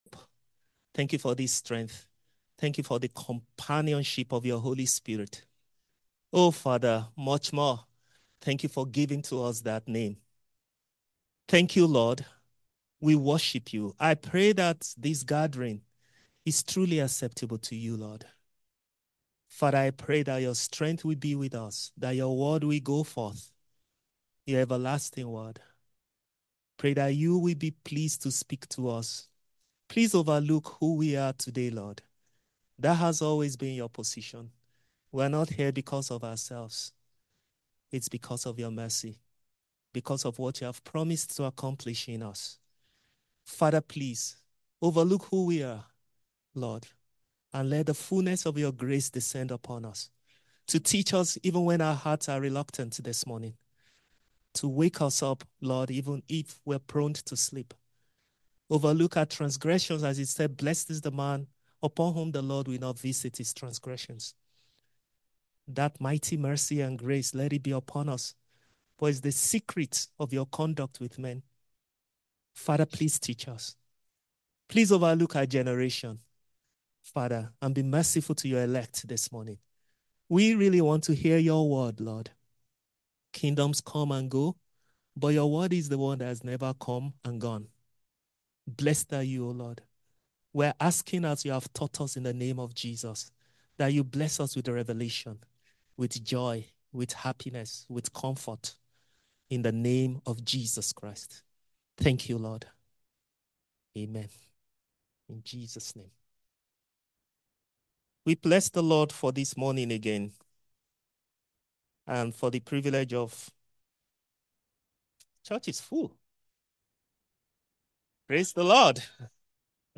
New Covenant Church, Maryland.
A message from the series "Sermons."